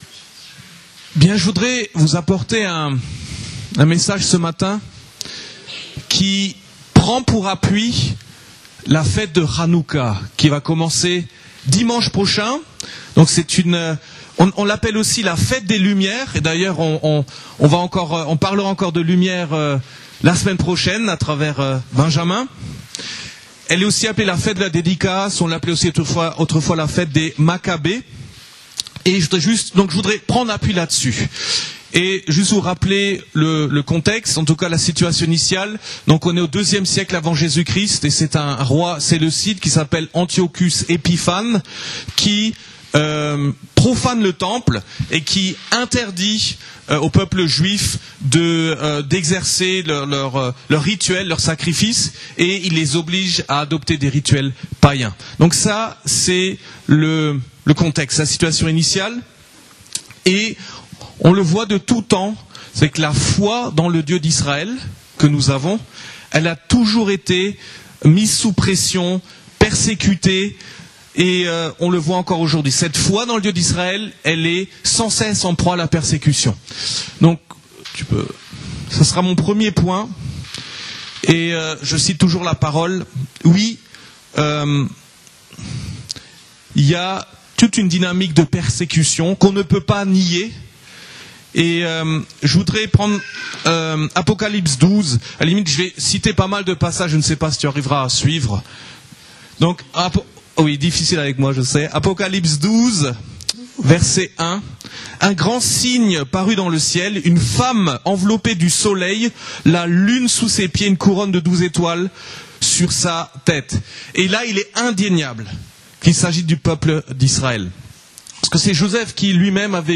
Type de service: Culte du dimanche